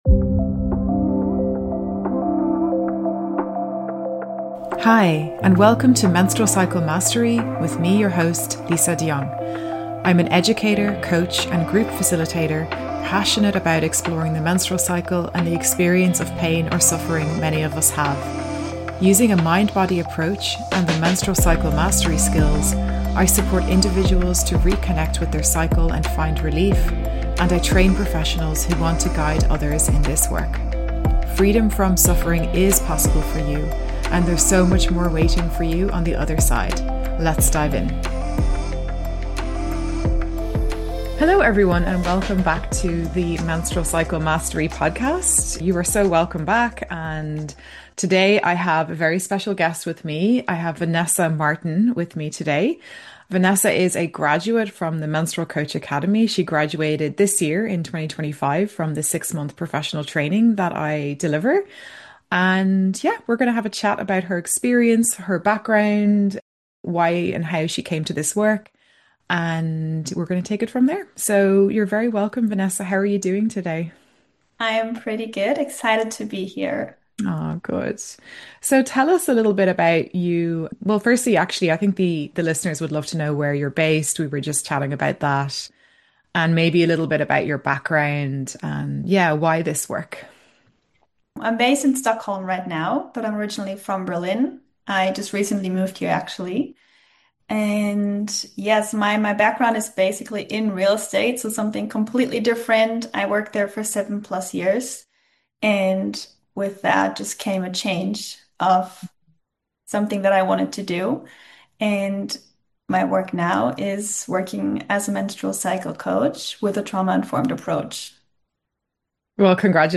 Tune in to join us for this nourishing conversation!